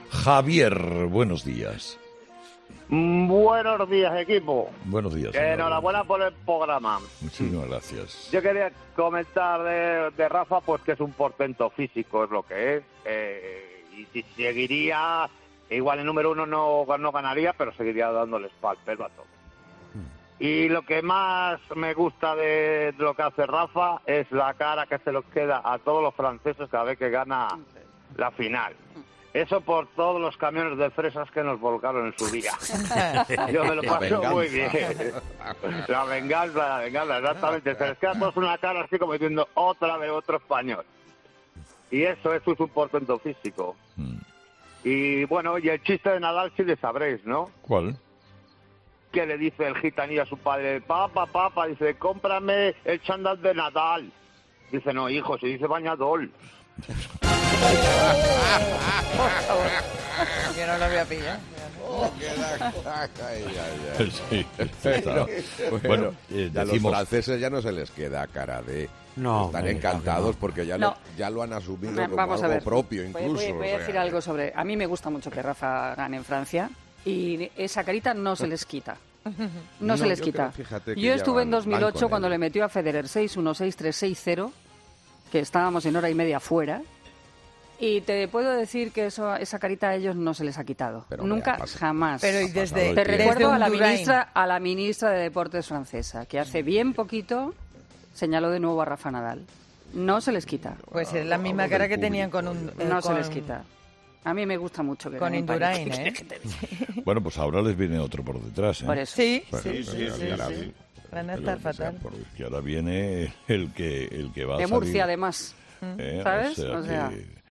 Un oyente confiesa qué le gusta más de Rafa Nadal y Carlos Herrera aplaude: "La cara que se les queda..."